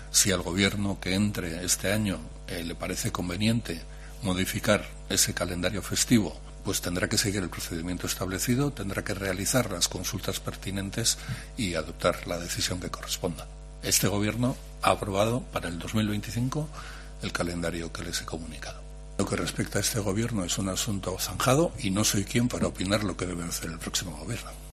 Bingen Zupiria, portavoz del Gobierno vasco, sobre el 8-M como posible festivo